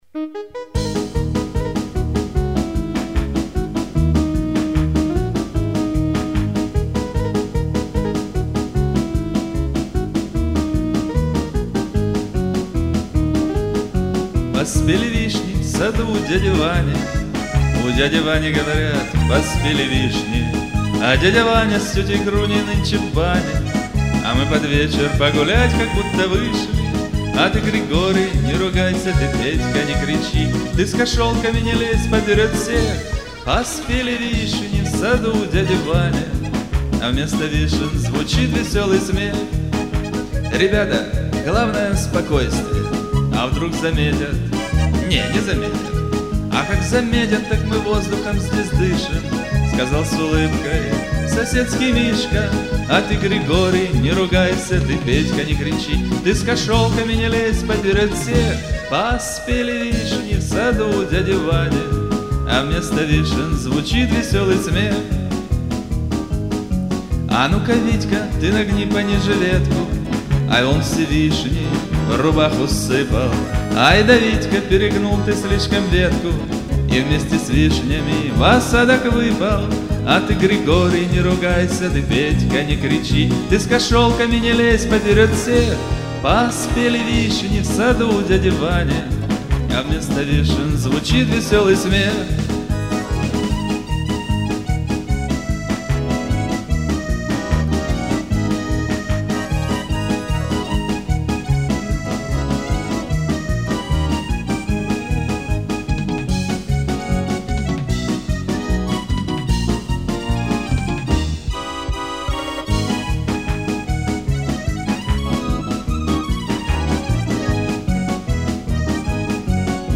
ресторанный вариант 3,613 Kb